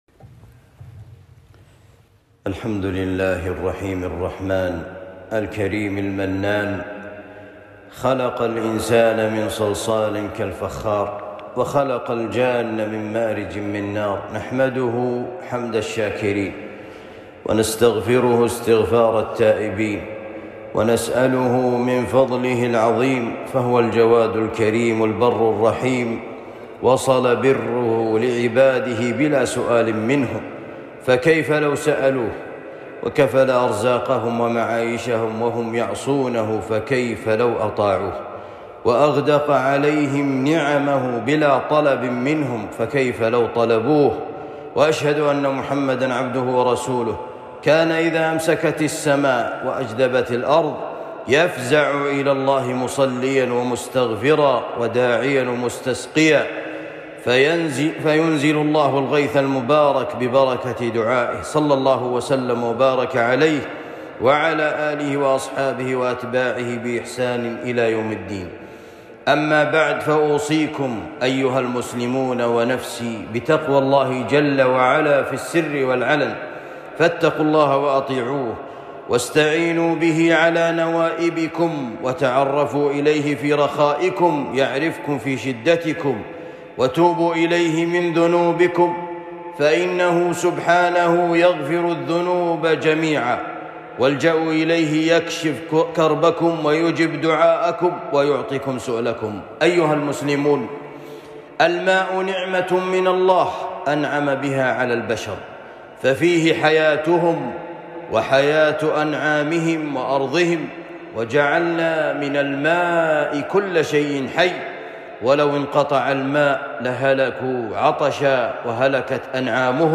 خطبة الإستسقاء